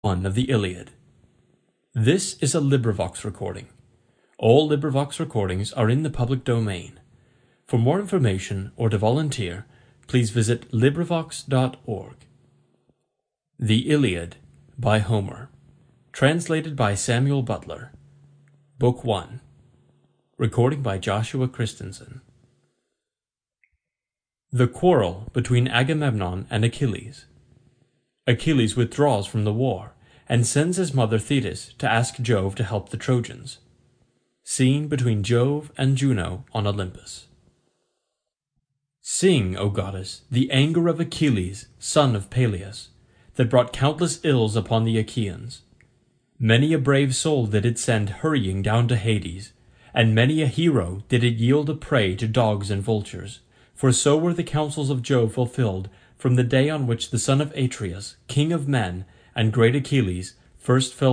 نویسنده: Homer ژانر: حماسی، شعر تعداد فایل صوتی: 24 فایل صوتی معادل بیش از 15 ساعت سطح: Intermediate (متوسطه به بالا)
کتاب صوتی انگلیسی The Iliad